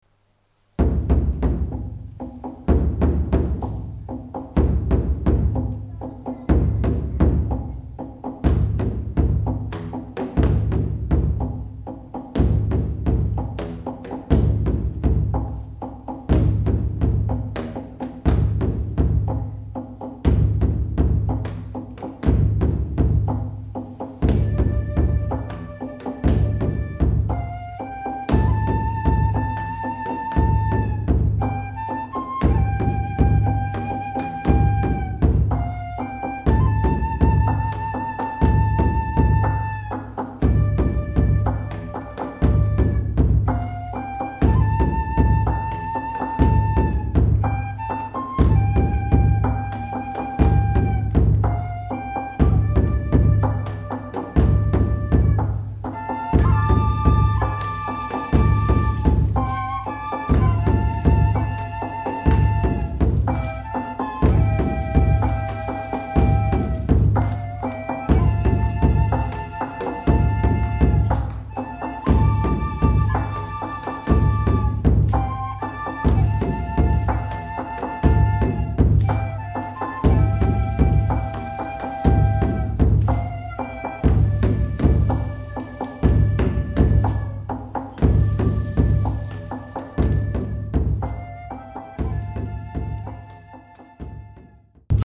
サンライズホール
ライヴ録音